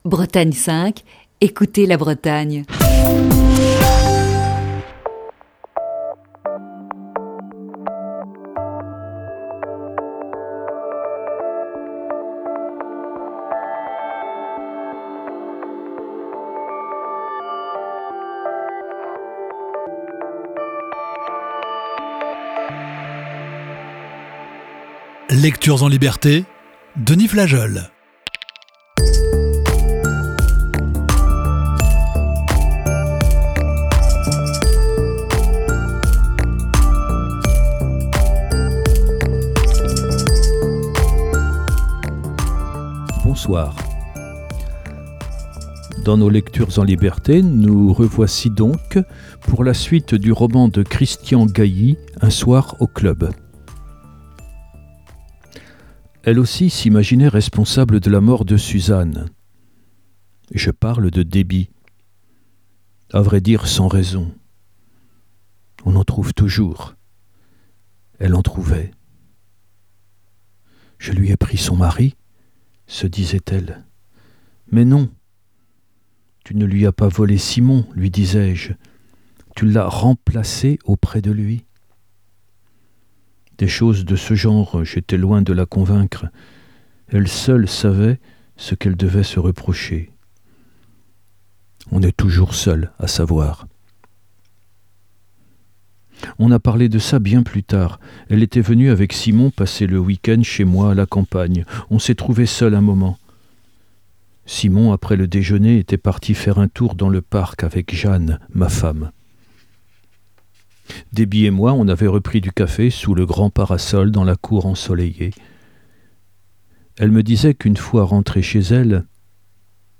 Émission du 9 octobre 2020.